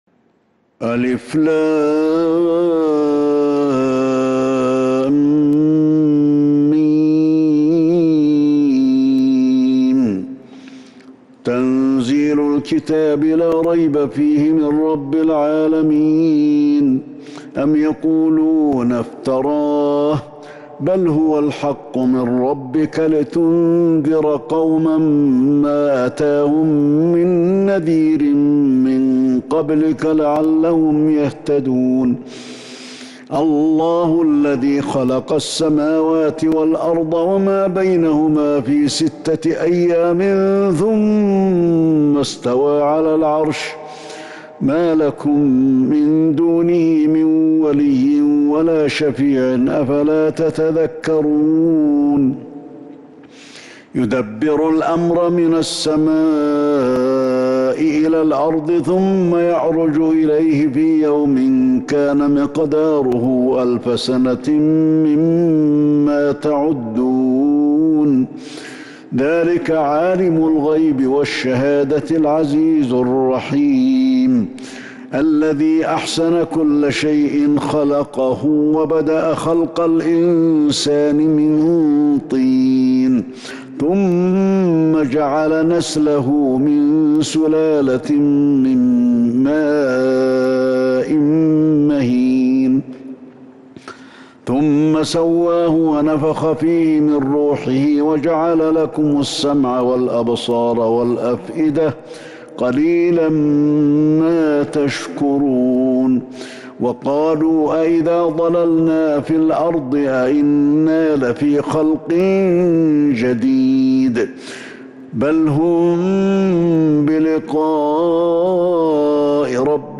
سورة السجدة كاملة من تراويح الحرم النبوي 1442هـ > مصحف تراويح الحرم النبوي عام 1442هـ > المصحف - تلاوات الحرمين